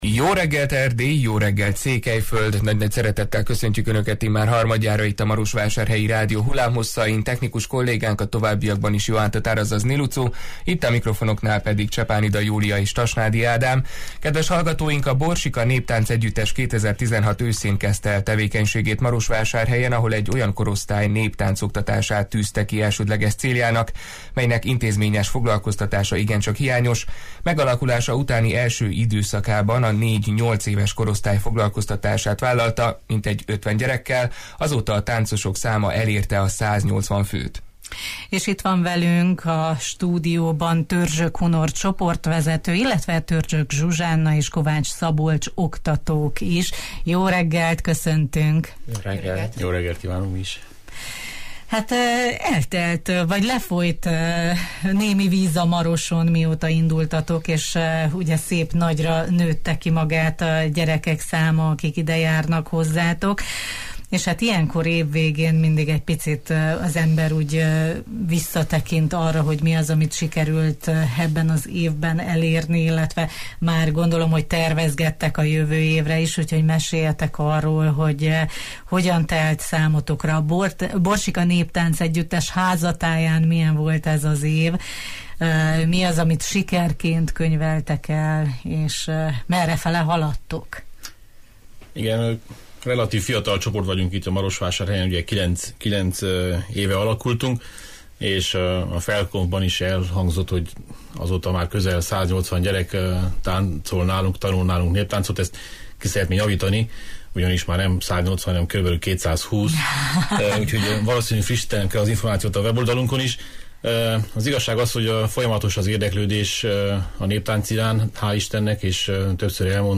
Stúdiónkban vendégül láttuk